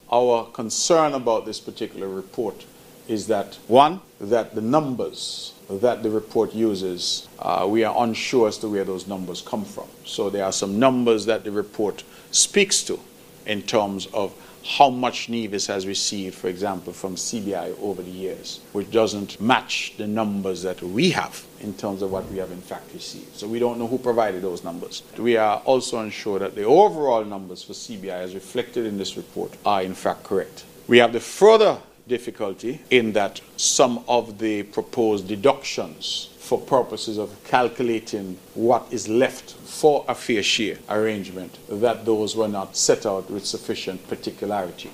During his press conference for the month of July, Premier Brantley stated that his cabinet had met and deliberated on the much-anticipated report from the World Bank, on how the revenue from the Citizenship by Investment Programme (CBI) should be shared between St. Kitts and Nevis.